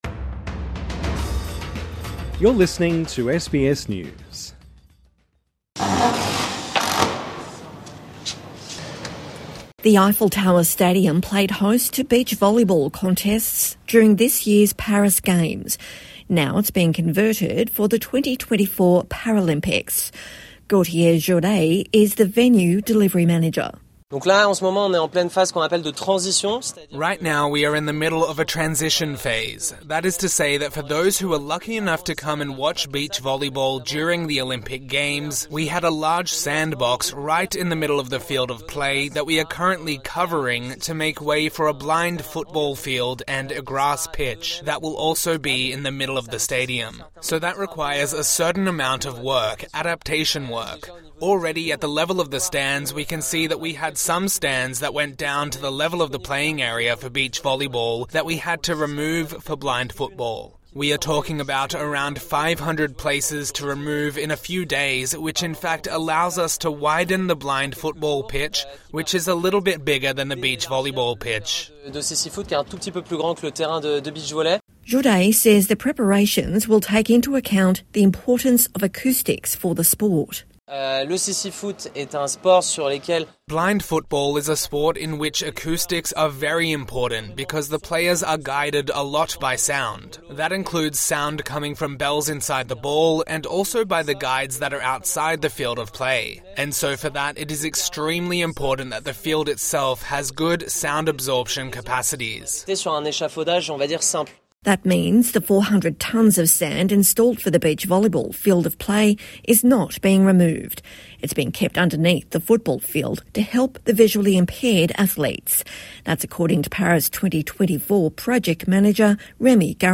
(Sound of drills and construction.)